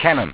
w3_cannon.wav